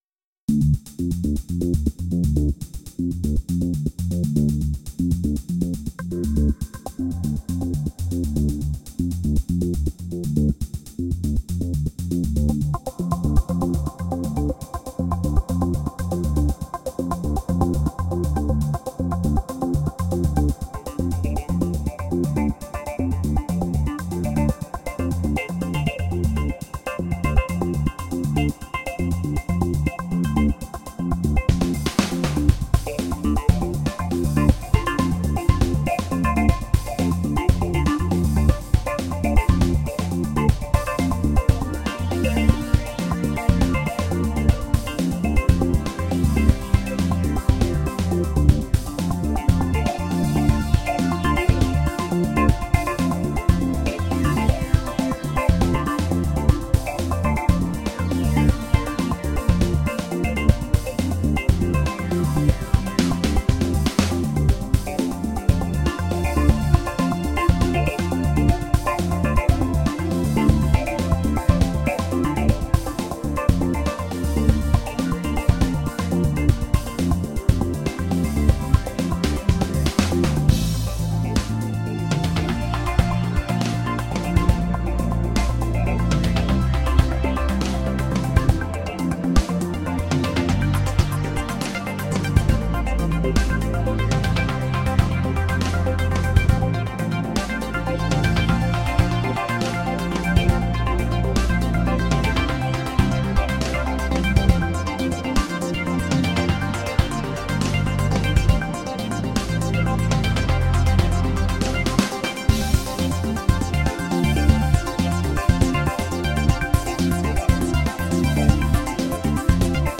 A demo piece
bass, arp guitar, synth sequence
synth chords